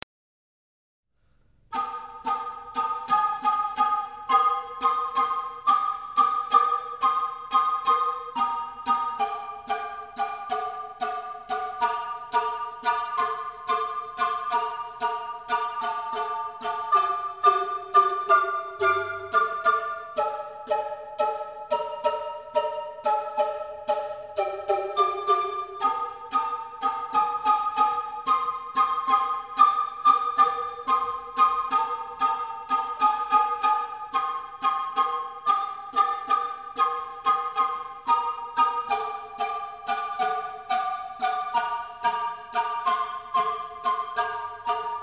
Obsazení: 3 Blockflöten (ATB)